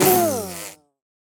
Minecraft Version Minecraft Version snapshot Latest Release | Latest Snapshot snapshot / assets / minecraft / sounds / mob / bee / death2.ogg Compare With Compare With Latest Release | Latest Snapshot